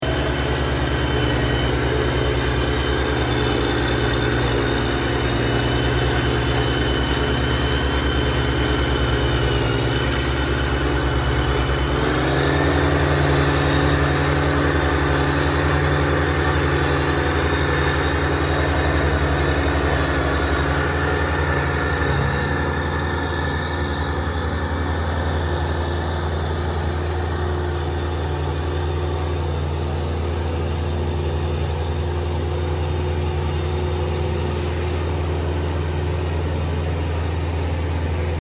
Ниже вы можете прослушать, а если очень надо, то и загрузить бесплатно звуки кошения травы бензиновой газонокосилкой.
Звук самоходной газонокосилки Kubota
samohodnaia-gazonok-kubota.mp3